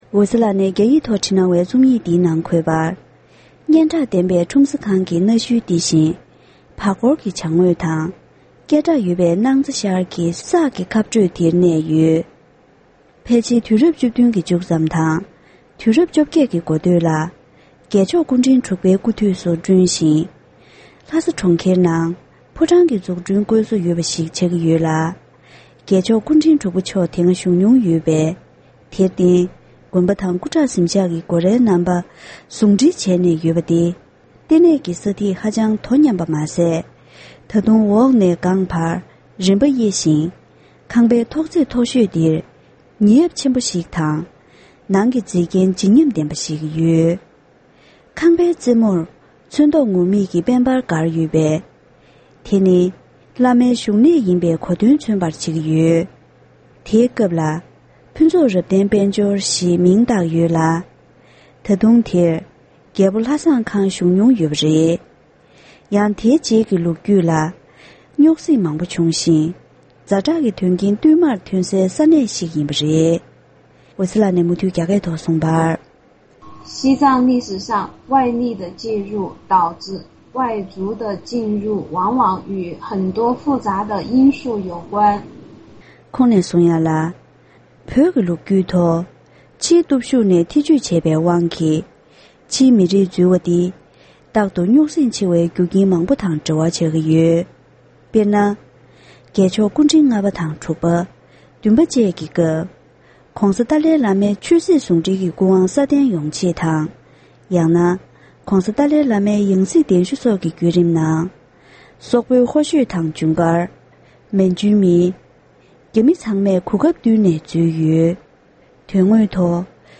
ཕབ་བསྒྱུར་གྱིས་སྙན་སྒྲོན་ཞུས་པར་གསན་རོགས༎